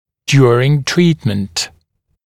[‘djuərɪŋ ‘triːtmənt][‘дйуэрин ‘три:тмэнт]во время лечения